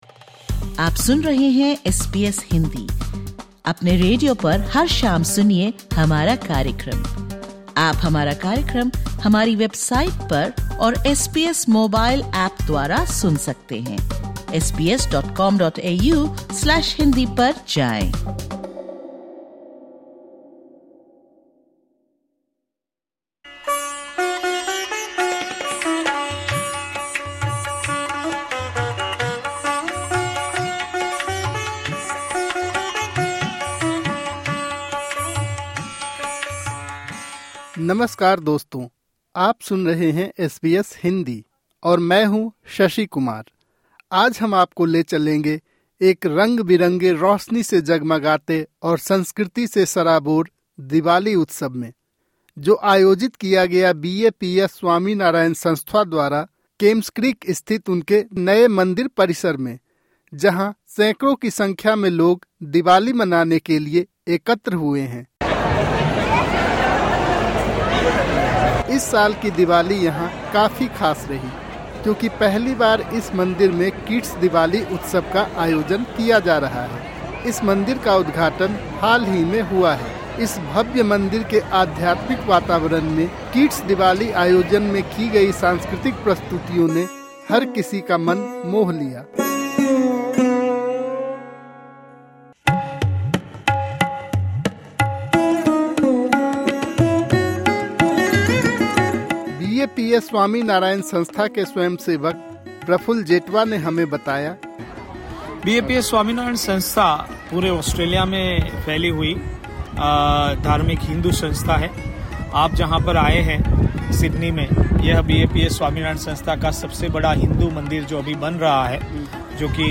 बीएपीएस स्वामीनारायण हिंदू मंदिर, केम्प्स क्रीक, सिडनी में दिवाली उत्सव।